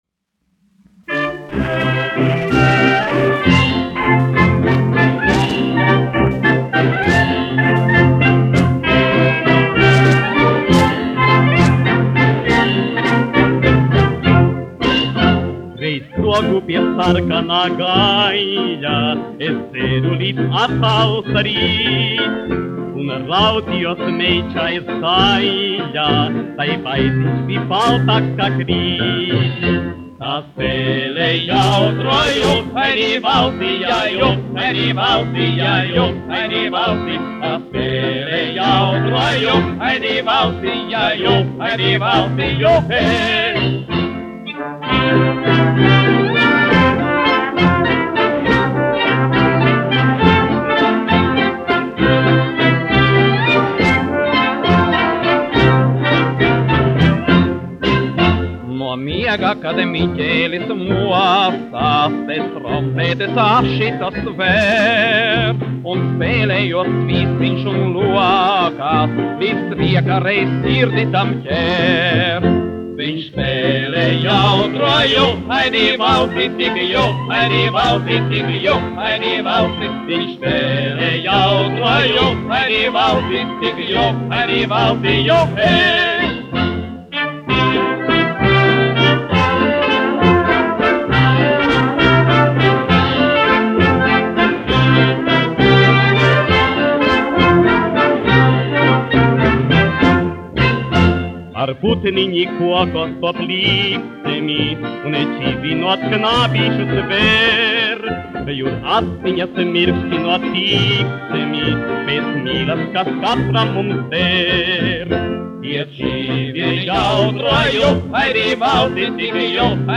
1 skpl. : analogs, 78 apgr/min, mono ; 25 cm
Populārā mūzika
Skaņuplate